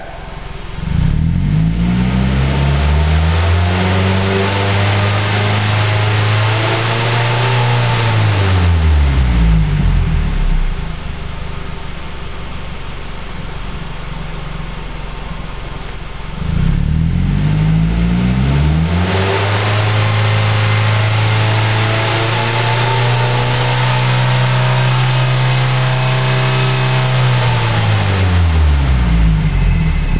Mazda SP23 Remus Exhaust
Mazda_SP23_Remus.wav